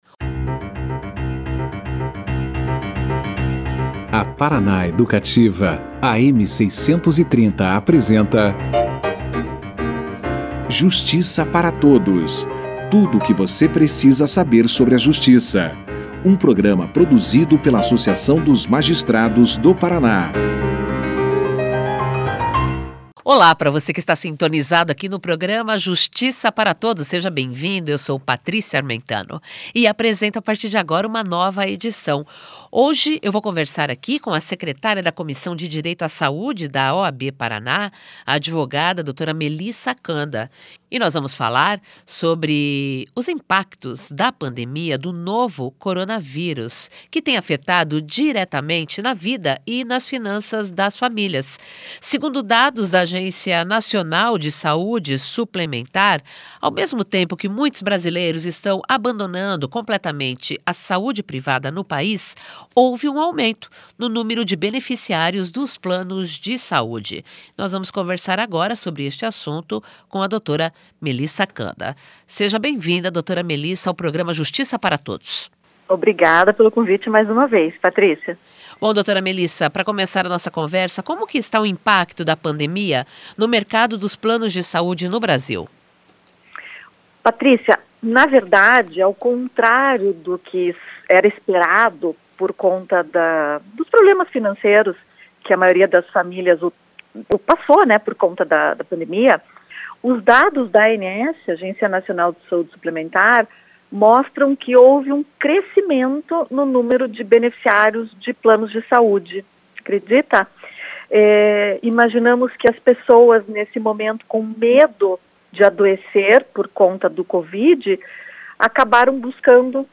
>>Clique Aqui e Confira a Entrevista na Integra<<